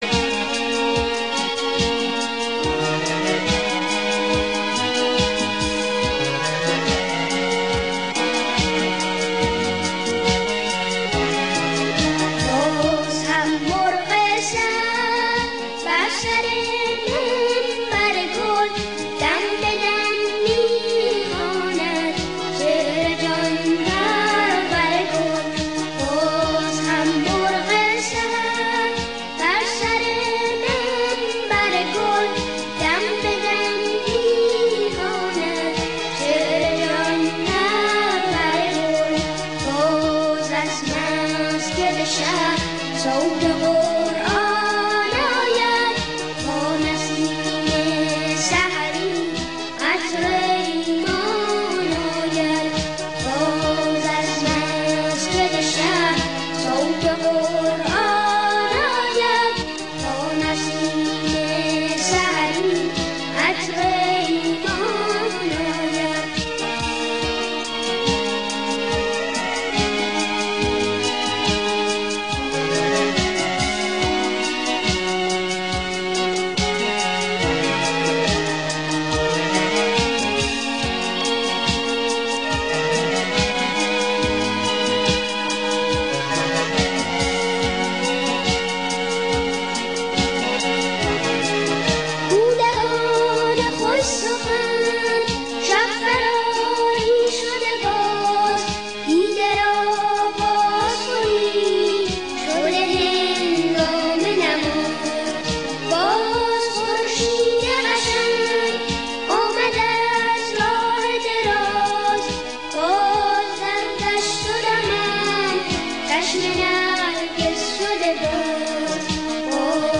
خیلی ها با این صدای زیبا و دل انگیز خاطره دارند.
ضبط شده در تلویزیون شیراز ۱۳۷۱